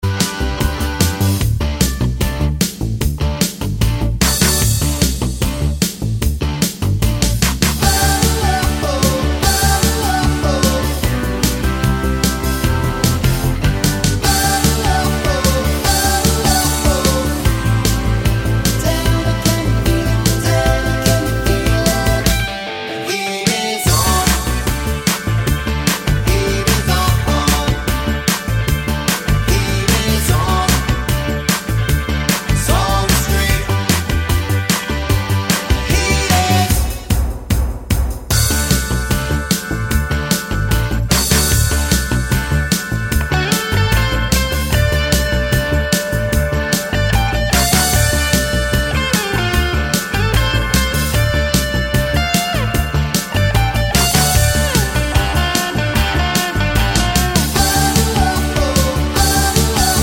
Minus Sax with Backing Vocals Pop (1980s) 3:57 Buy £1.50